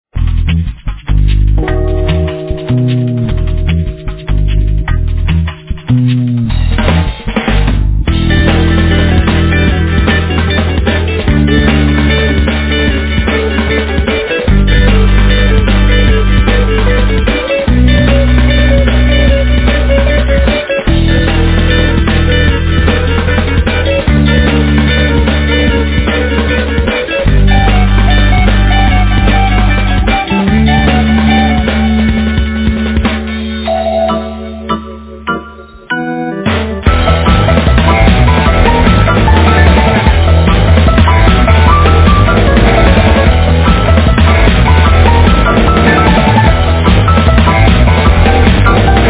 Vocals, Guitar
Guitar, Guitar synth
Drumas
Bass
Synthesizer, Guitar, Jew's harp, Violin, Percussions
Piano
Vocals, Mouth organ
Synthesizer, Machine-organ, Sitar, Violin, Percussions